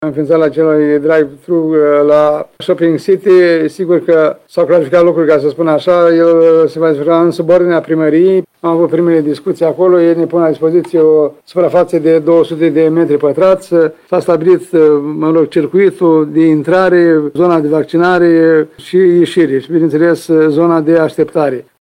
Centrul drive-thru din orașul Suceva ar urma să fie deschis la finalul acestei săptămâni, mai spune primarul orașului, Ion Lungu: